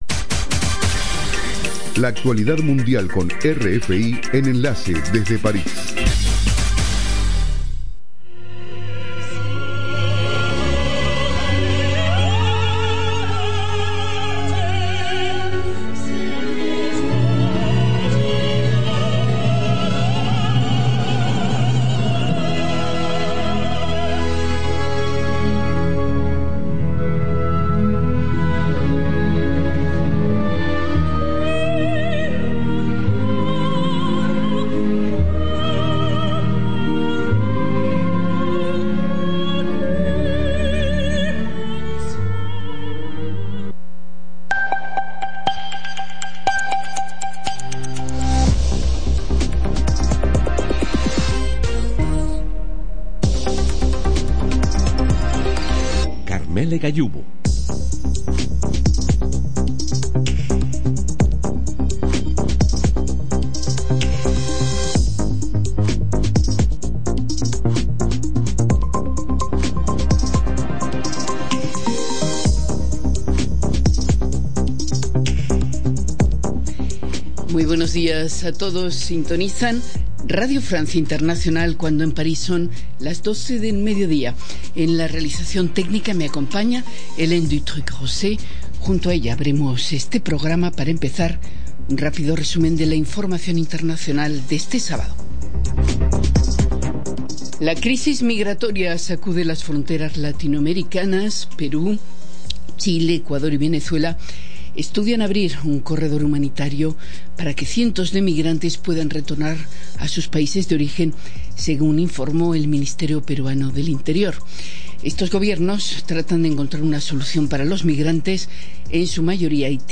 Programa: RFI - Noticiero de las 07:00 Hs.